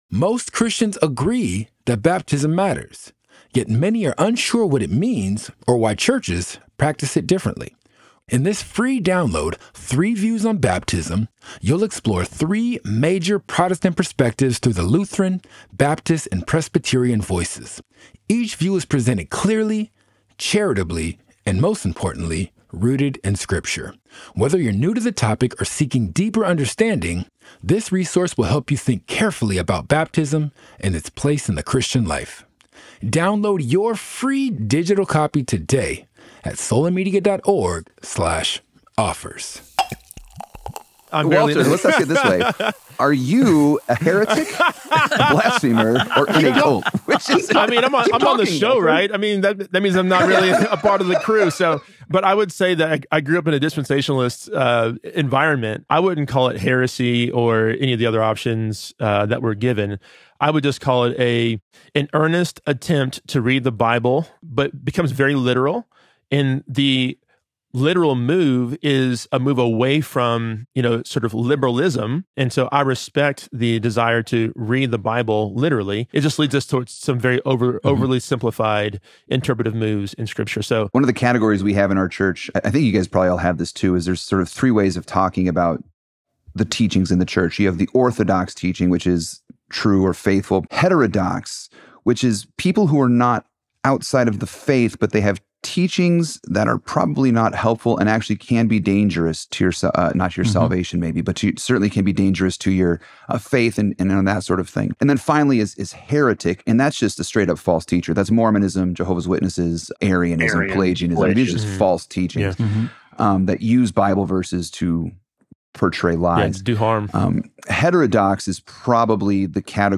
Series: Audience Q&A
Question & Answer